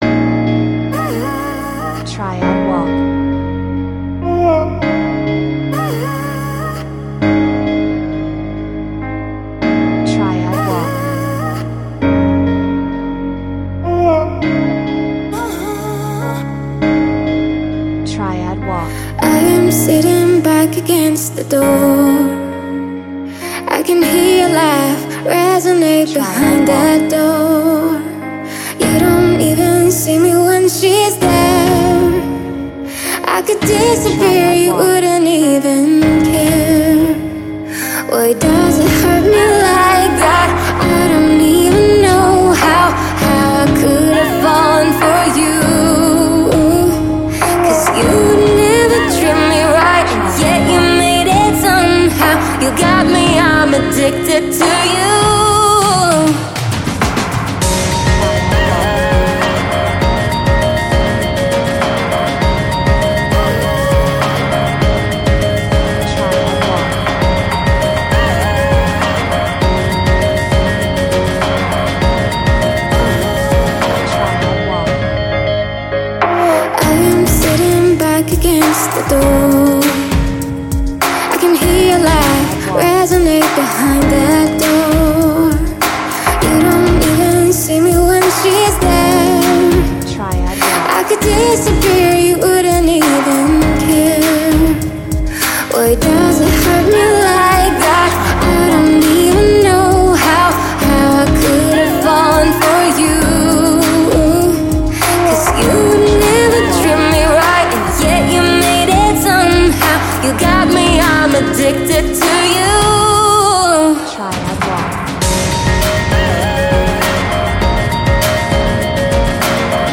Pop , 女性ボーカル , 映像 , エレクトリックピアノ , R&B
情熱的 , ドラム